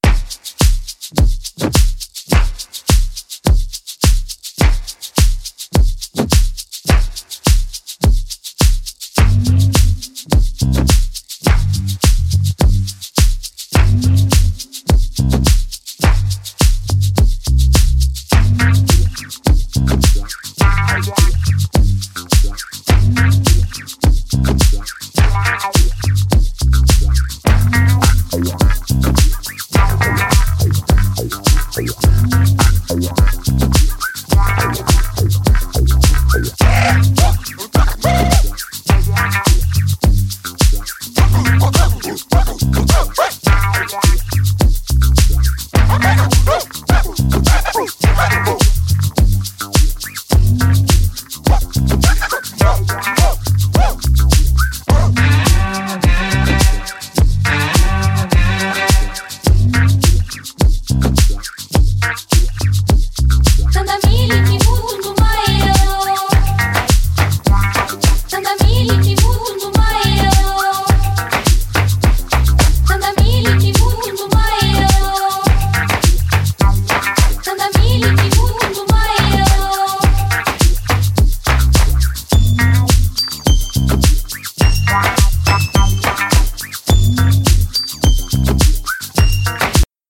個性的なスロー・トライバル〜ワールド・ミュージックっぽいオリジナルをスタイリッシュにクラブ・トラック化！